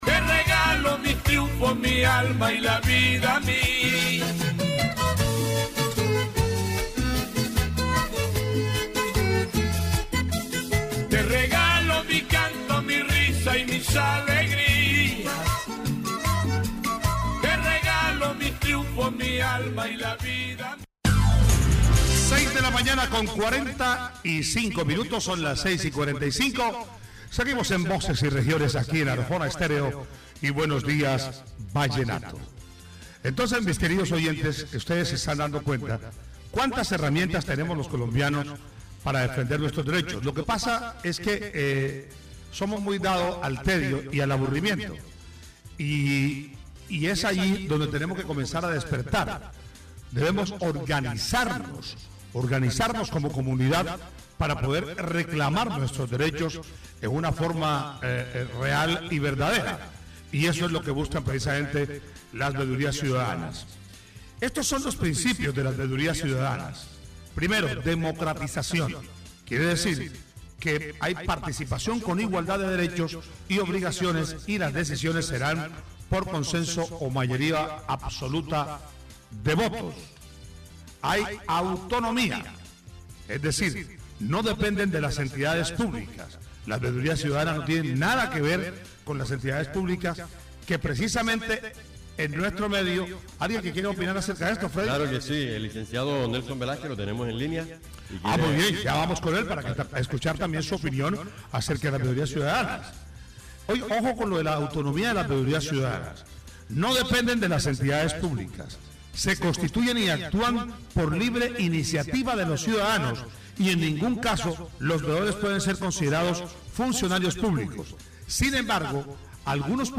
Líderes comunitarios y expertos en el tema resaltan la autonomía de estas organizaciones y su papel en la construcción de comunidades más informadas y organizadas. Se discuten casos concretos de Arjona donde la veeduría ha sido clave para exigir rendición de cuentas y mejorar la gestión pública.
Programas de radio , Bolívar (Región, Colombia) -- Grabaciones sonoras , Veeduría comunitaria , Rendición de cuentas